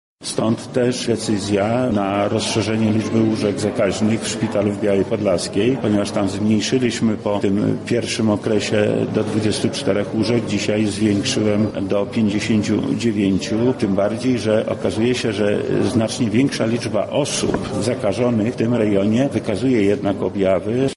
Widać, że koronawirus w tym przypadku jest dosyć agresywny — mówi wojewoda lubelski Lech Sprawka